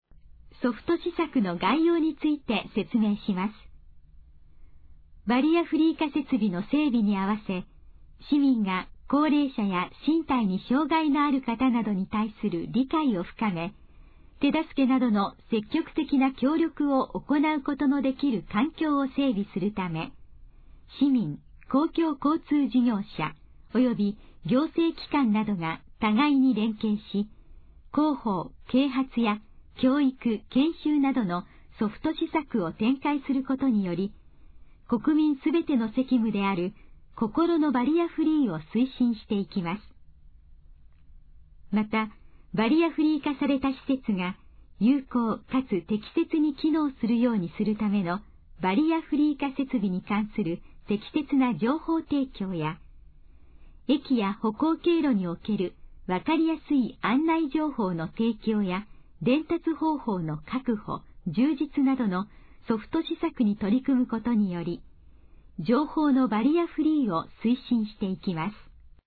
以下の項目の要約を音声で読み上げます。
ナレーション再生 約135KB